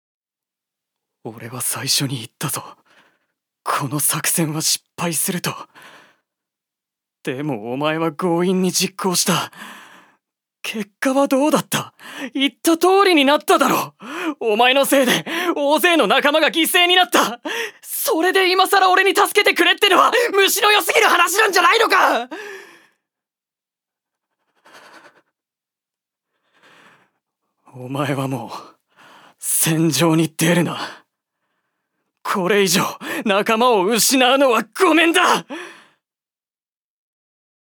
所属：男性タレント
セリフ５